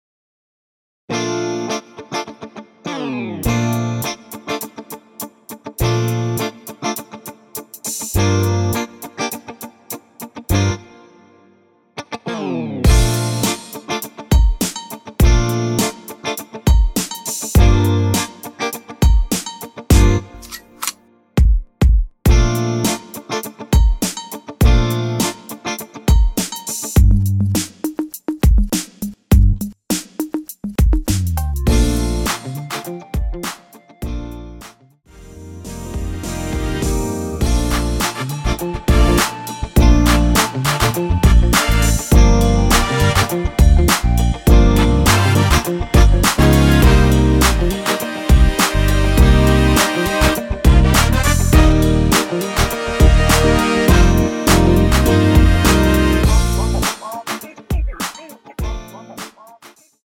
전주 없이 시작 하는 곡이라서 1마디 전주 만들어 놓았습니다.(미리듣기 확인)
3초쯤 노래 시작 됩니다.
원키에서(-3)내린 멜로디 포함된 MR입니다.(미리듣기 확인)
Bb
앞부분30초, 뒷부분30초씩 편집해서 올려 드리고 있습니다.
중간에 음이 끈어지고 다시 나오는 이유는